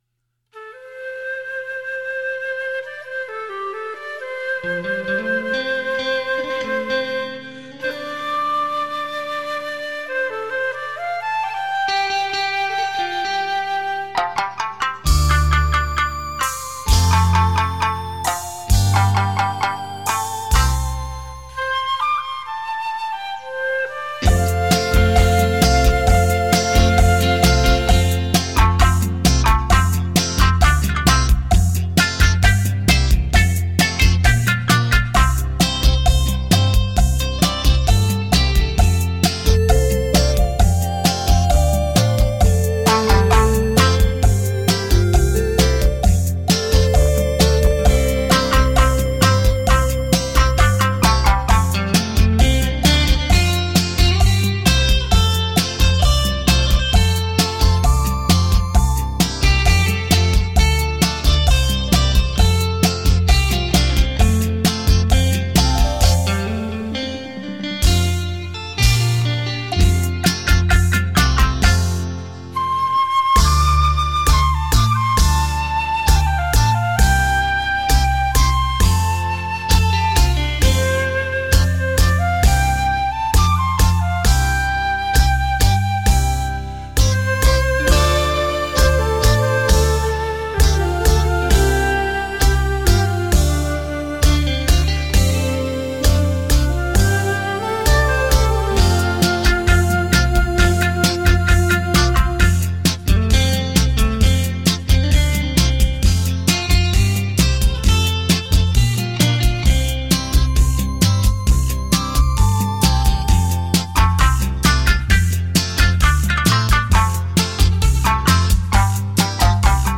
数位录音 品质保证